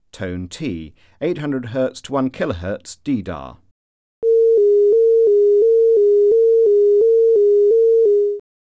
Alert Tone: T